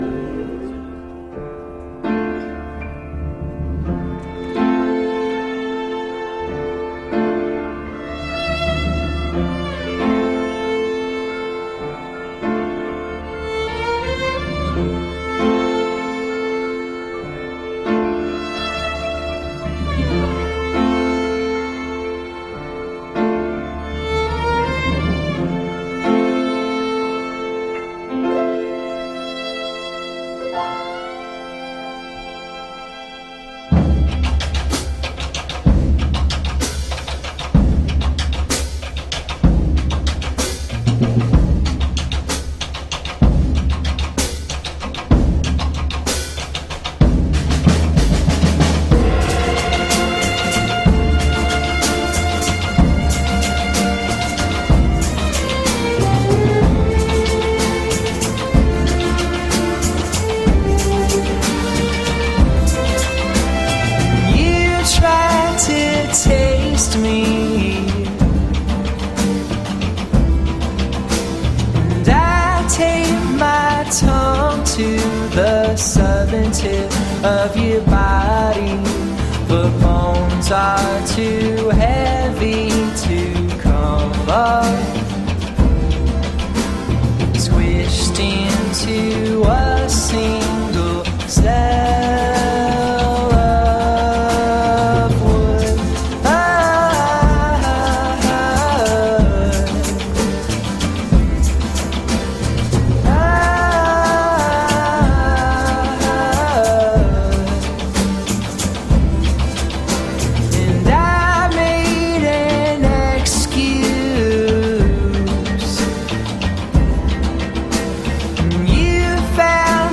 from the orchestral arrangment
the vocal breakdown